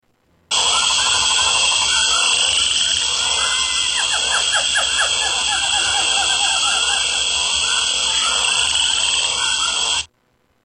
Rainforest Ambience 5
Category: Animals/Nature   Right: Personal